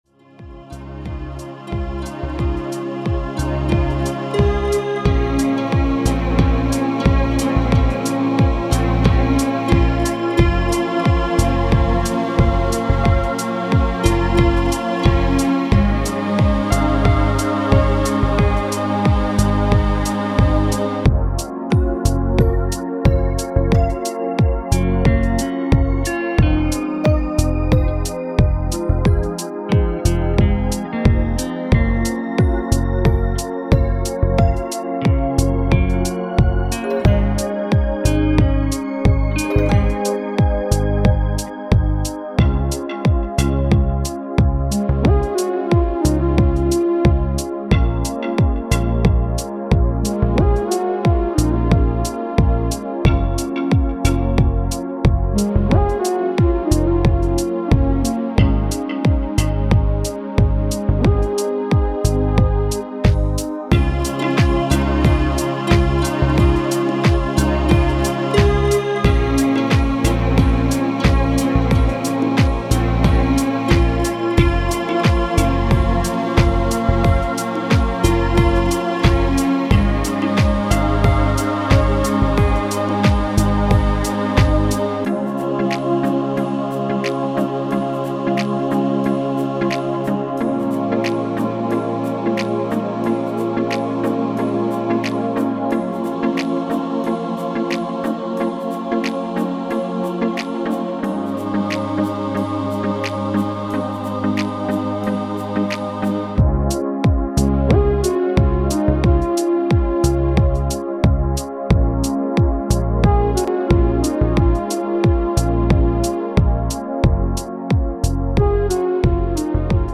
melodische Kompositionen